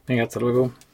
描述：A girl talking on the phone. The sound has been cleaned and compressed to sound brighter.
标签： talk voice spanish female conversation vocal phone woman
声道立体声